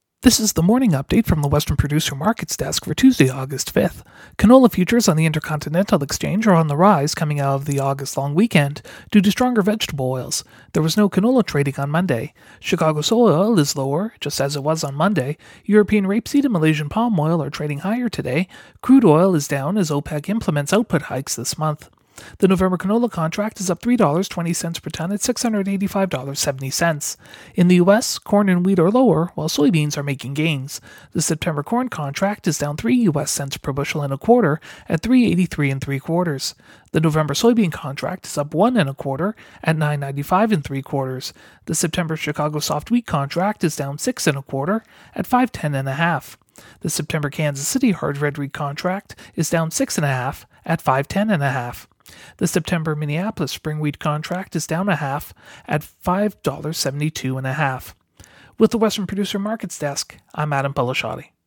MarketsFarm's radio show is delivered twice per day - at noon and at the close of markets - and contains the latest information on the price of canola, wheat, soybeans, corn and specialty crops.